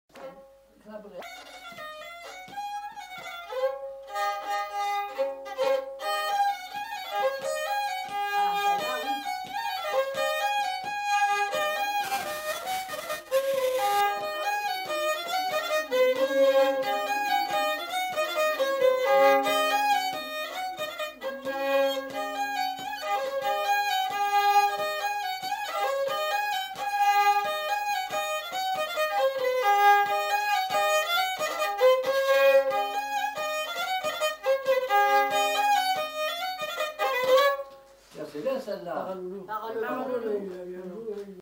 Aire culturelle : Limousin
Genre : morceau instrumental
Instrument de musique : violon
Danse : bourrée